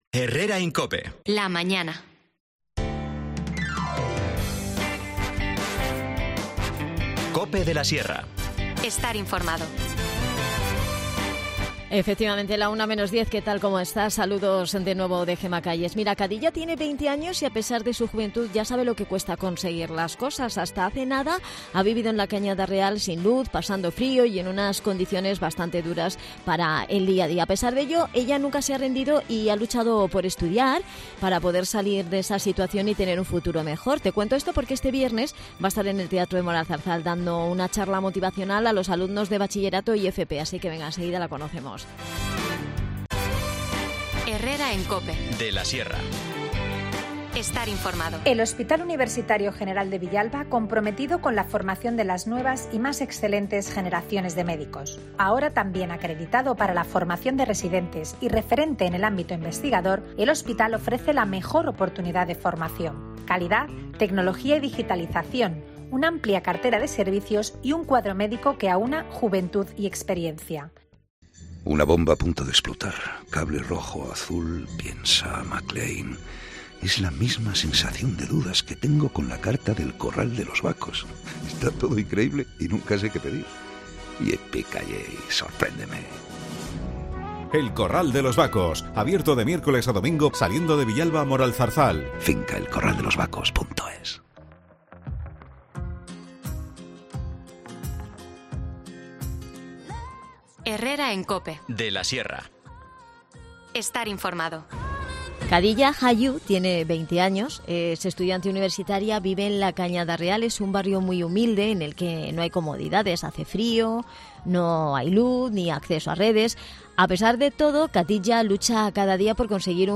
Charlamos con ella en el programa para conocemos su historia de superación.